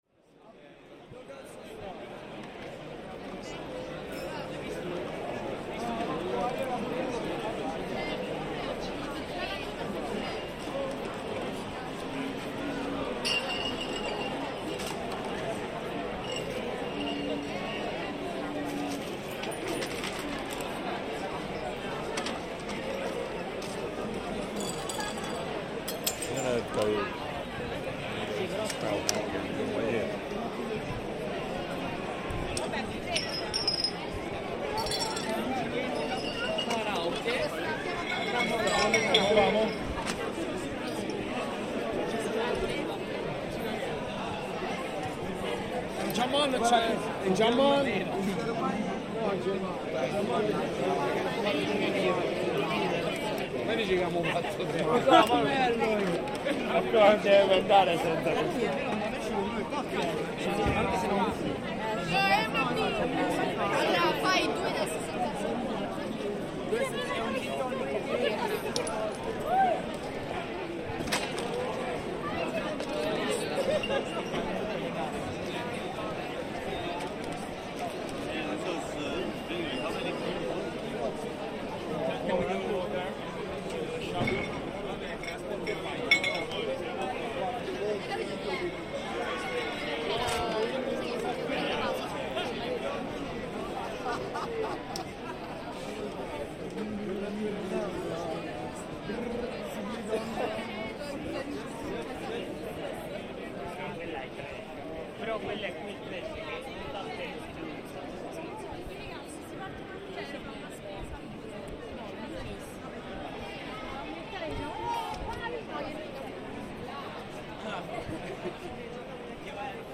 A classic Saturday night in the hectic Piazza San Calisto in Trastevere, Rome, with breaking glass, bottles rolling around and the chaotic noise of hundreds of conversations among different groups of people drinking and socialising at various bars.